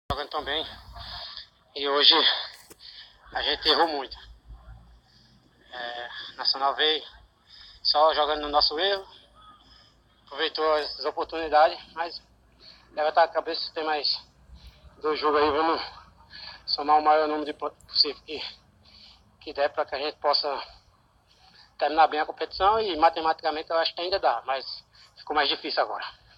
Ao final da partida, o meia Marcelinho Paraíba lamentou a derrota da Perilima que fica longe da classificação.